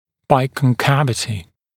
[ˌbaɪkɔn’kævətɪ][ˌбайкон’кэвэти]двухсторонняя вогнутость, вогнутость с двух (противоположных) сторон
biconcavity.mp3